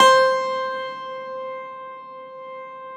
53e-pno14-C3.wav